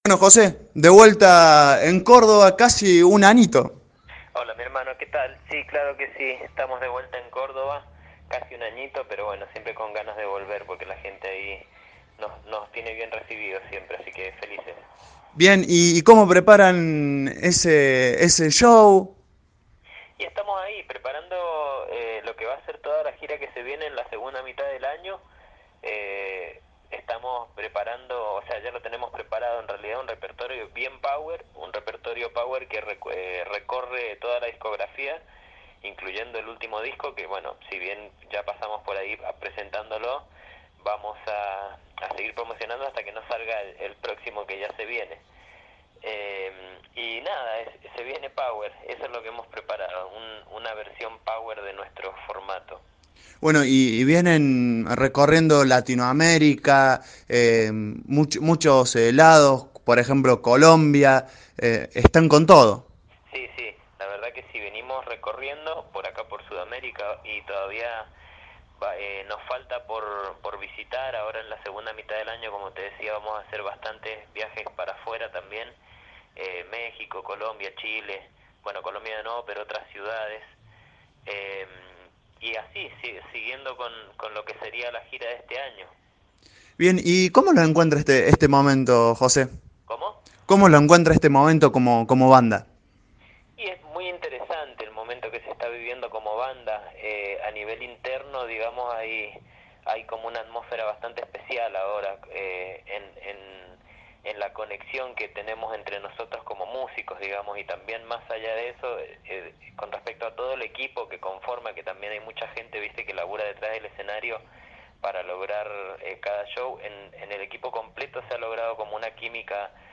Entrevista-Zona-Ganjah.mp3